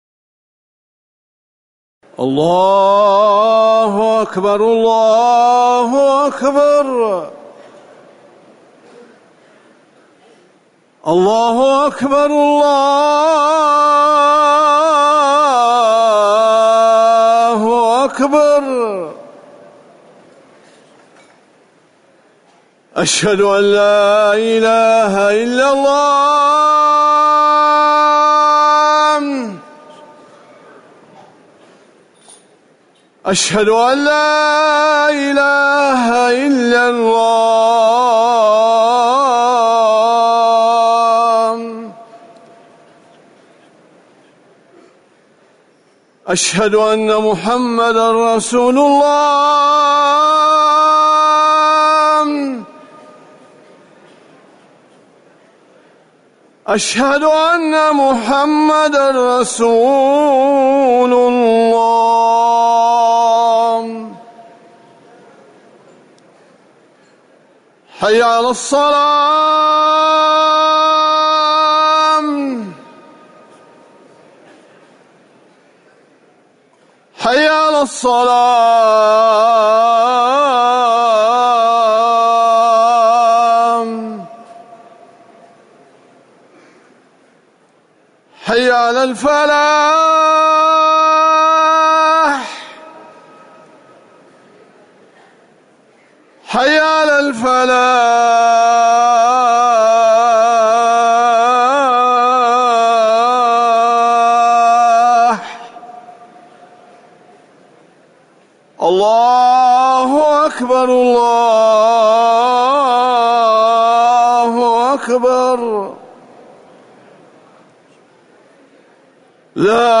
أذان المغرب - الموقع الرسمي لرئاسة الشؤون الدينية بالمسجد النبوي والمسجد الحرام
تاريخ النشر ٦ محرم ١٤٤١ هـ المكان: المسجد النبوي الشيخ